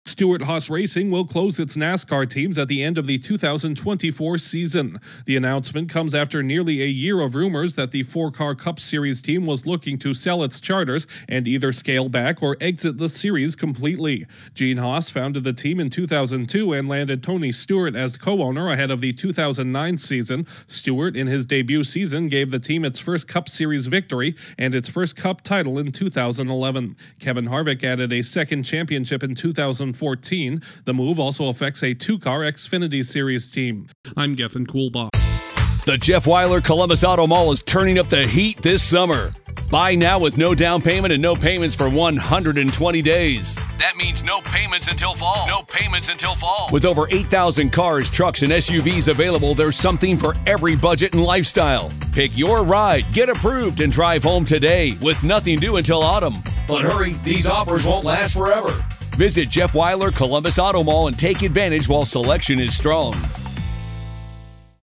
One of NASCAR’s most recognizable Cup Series teams will soon be shuttering its doors. Correspondent